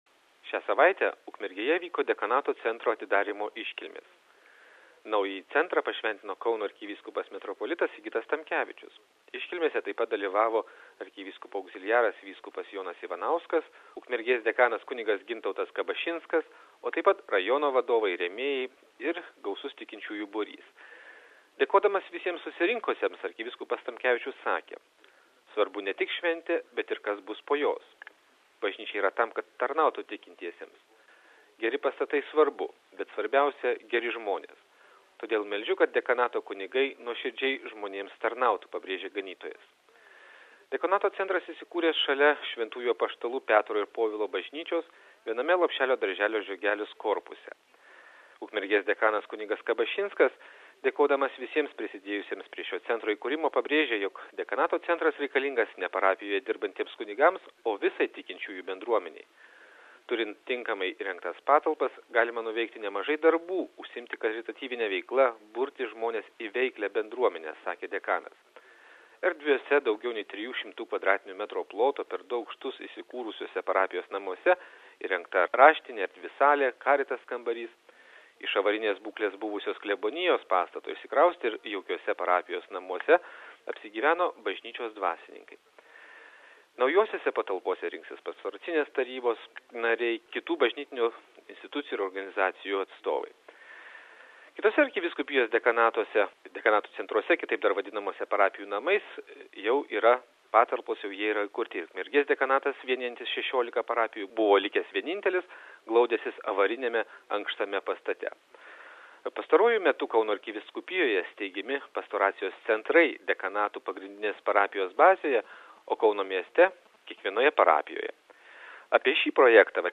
kalbina Kauno arkivyskupoaugzilijarą ir generalvikarą,vysk.JonąIvanauską: